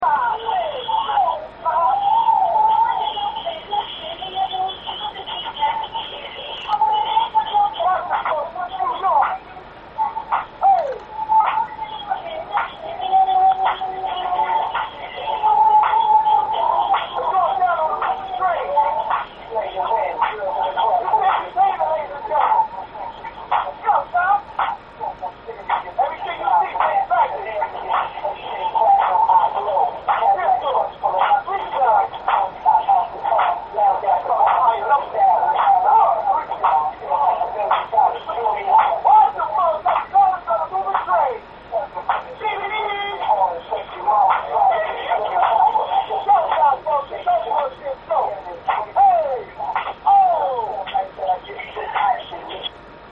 Subway nach JFK (samt einer
Street-Dance-Einlage von ein paar für ein Baseballteam sammelnden Rappern - MP3, 240kb), und weiter gehts mit einem netten kleinen Auteli Richtung Norden.
subwayrapper.mp3